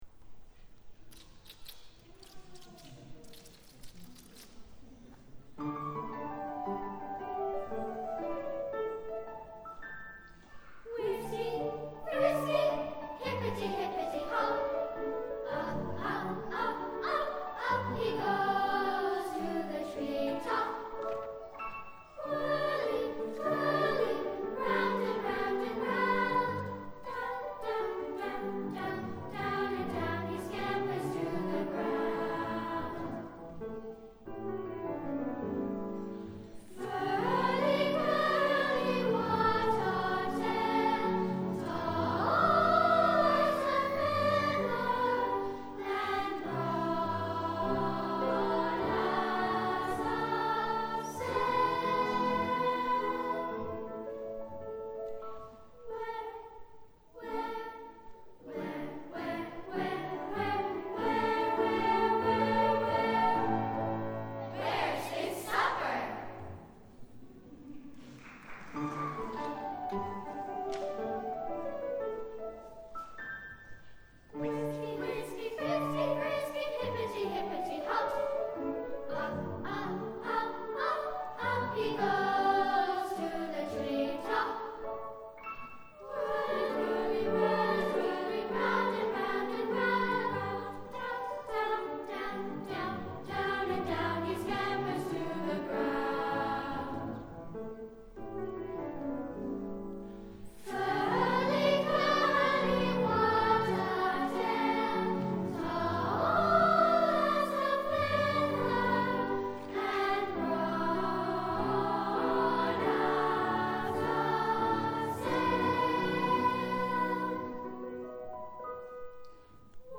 For: SA with Piano
A playful piece for young beginner choristers.